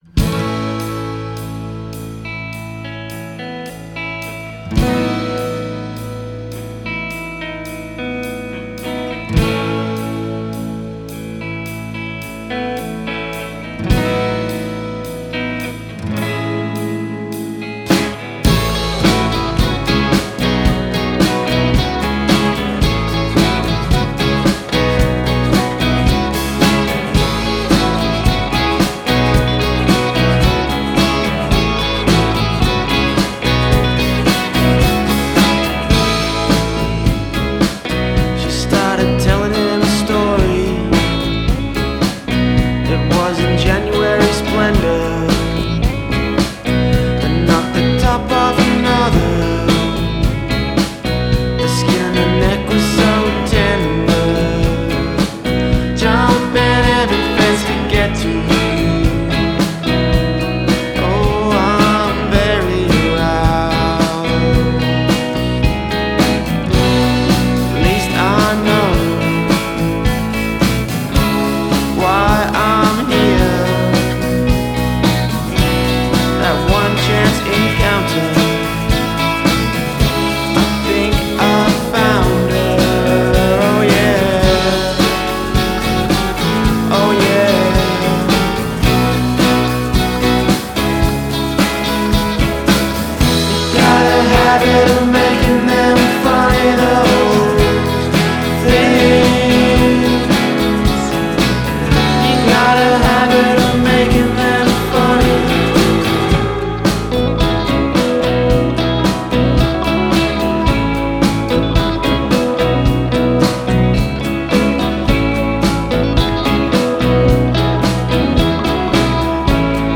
garage pop outfit
but the standout track is actually the mid-tempo head-bopper